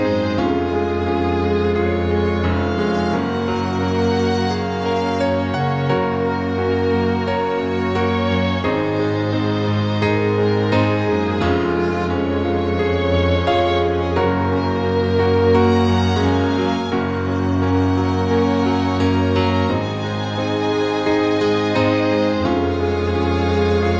Multiplex Lead Version